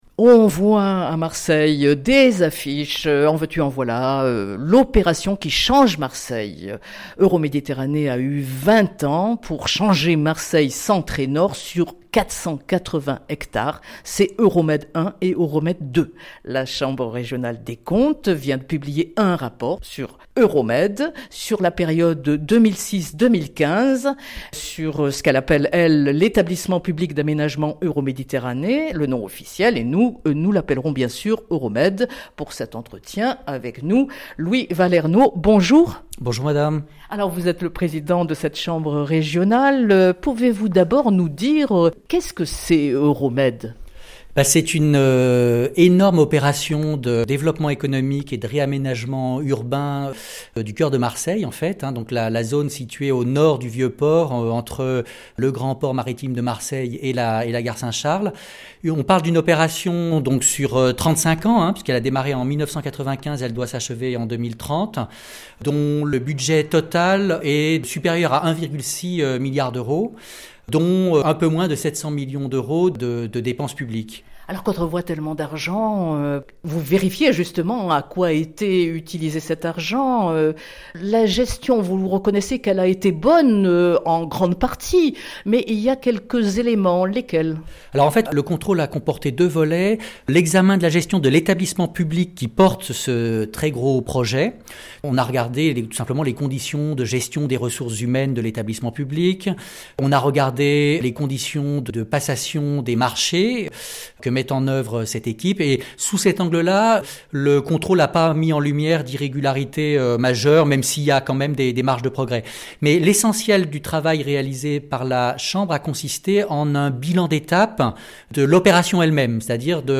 Entretien: Louis Vallernaud, Président de la CRC Paca revient sur le rapport consacré à Euroméditerranée